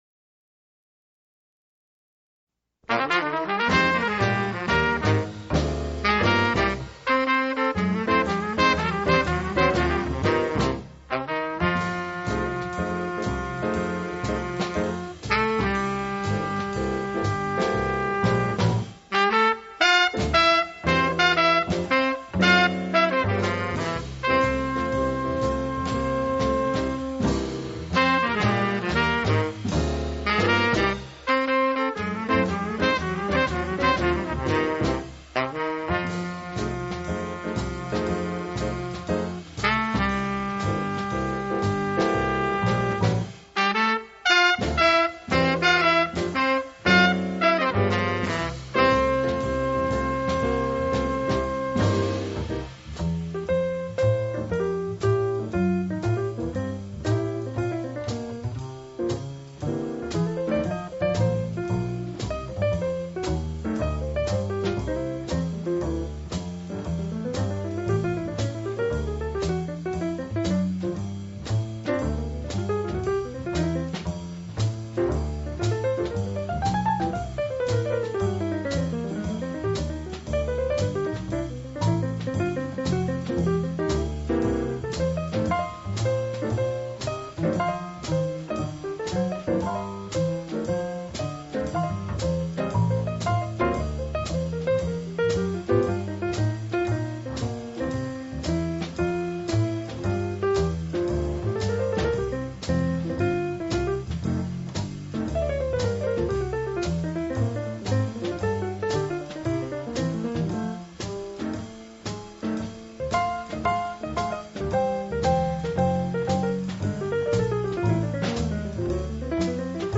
JAZZ PIANO SOLOS - AUDIO FOR SOLO TRANSCRIPTIONS